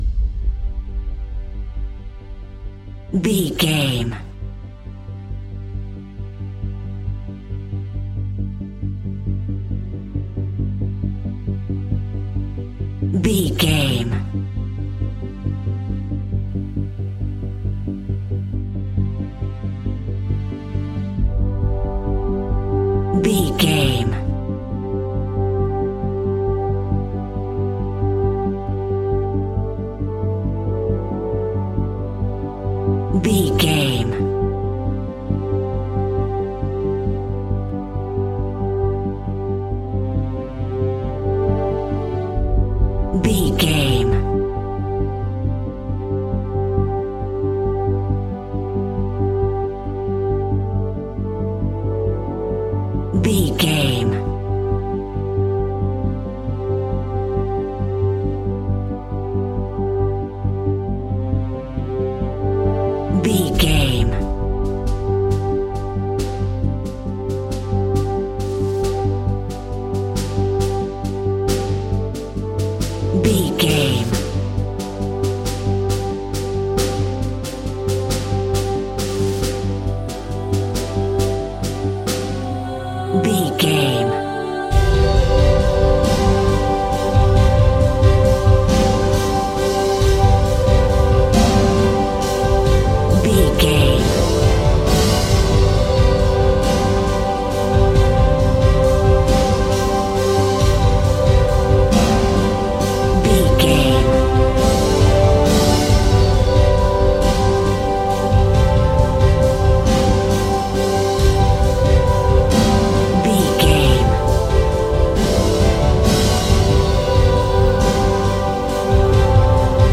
Ionian/Major
dramatic
epic
strings
percussion
synthesiser
brass
violin
cello
double bass